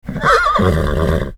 Horse Hee Haw 2